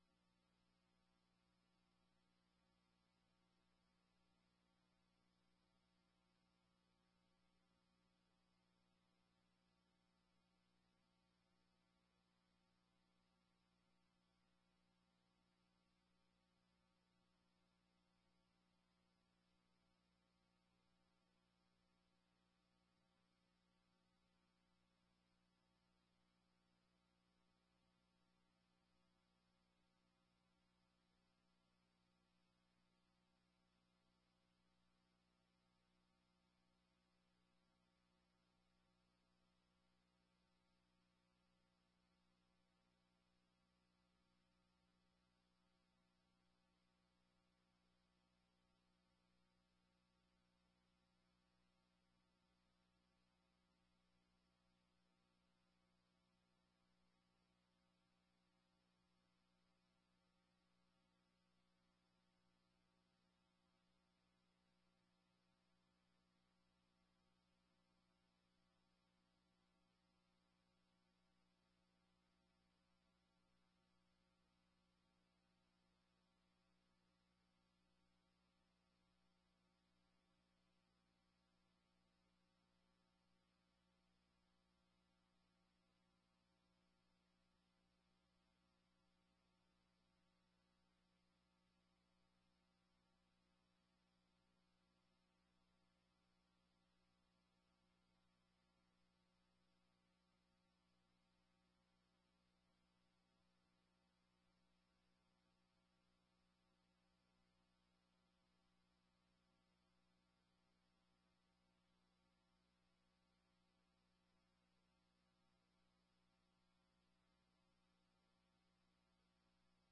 26ª Sessão Ordinária de 2019